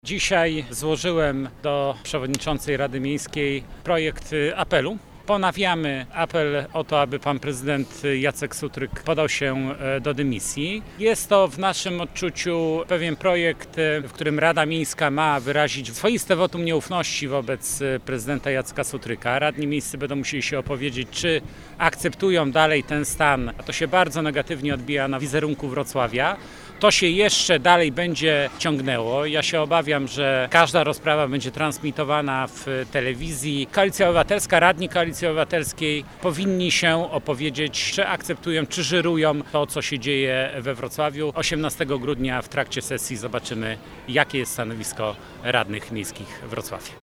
Radni chcą podjęcia tematu dymisji podczas obrad na grudniowej sesji. Jak zaznacza przewodniczący klubu PiS Łukasz Kasztelowicz, pismo w tej sprawie zostało już złożone.
03_radny-Kasztelowicz.mp3